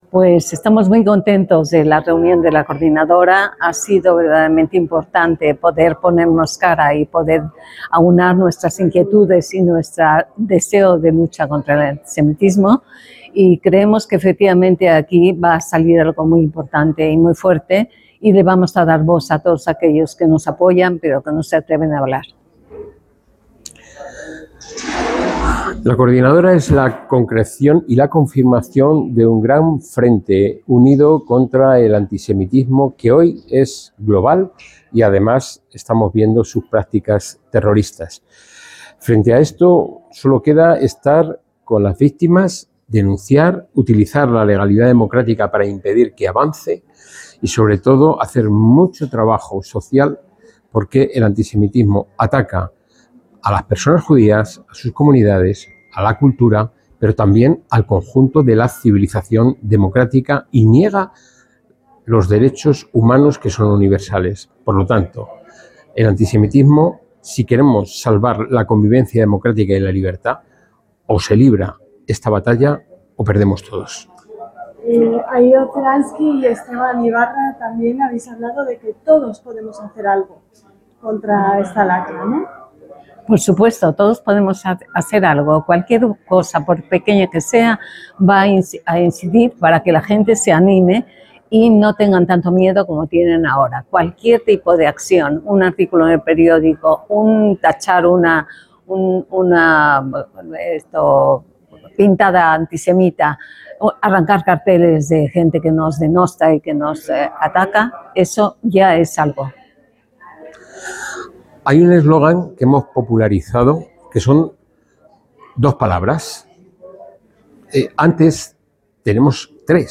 EL REPORTAJE